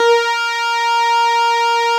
Added synth instrument
snes_synth_058.wav